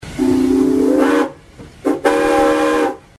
Train Steam Whistle Sound Button | Sound Effect Pro
Instant meme sound effect perfect for videos, streams, and sharing with friends.